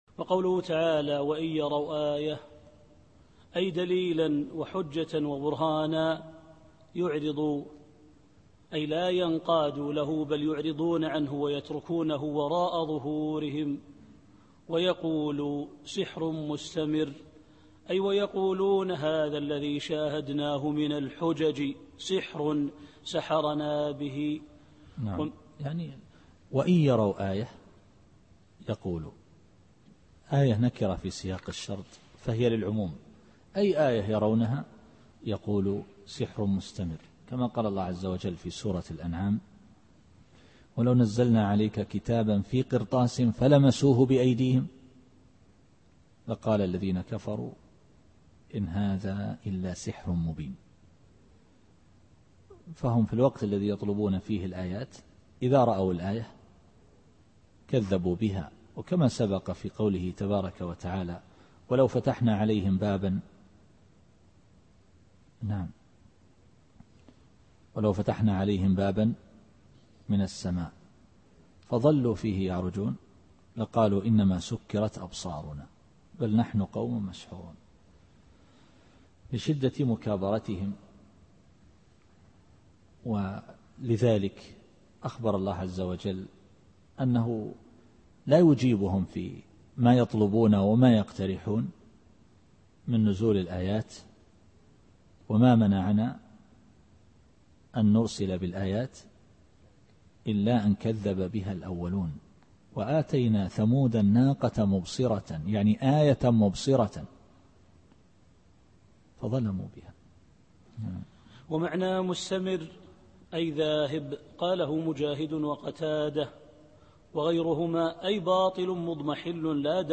التفسير الصوتي [القمر / 2]